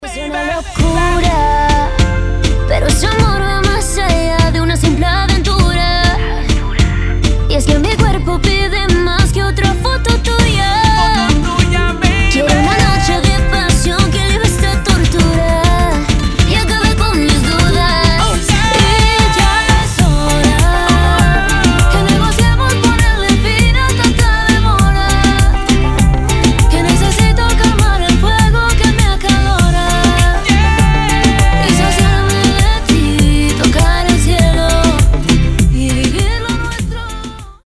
• Качество: 320, Stereo
зажигательные
заводные
красивый женский голос
Latin Pop